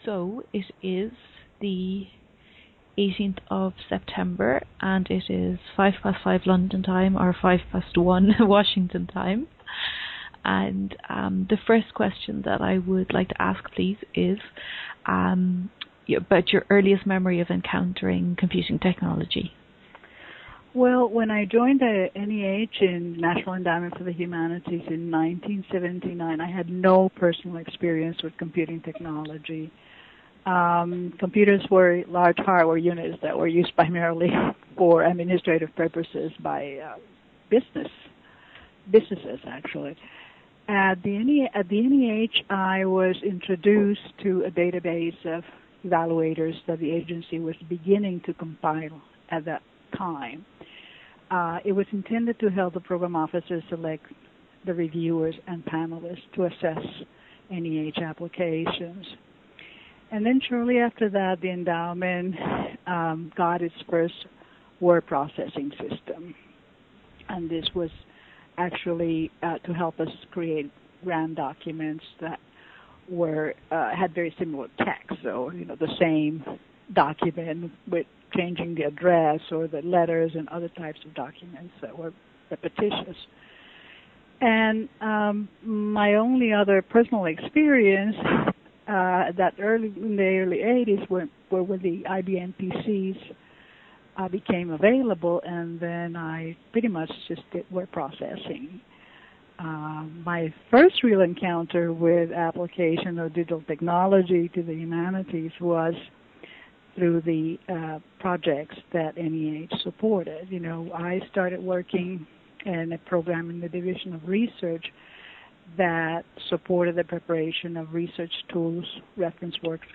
Skype interview
An oral history interview for the Hidden Histories project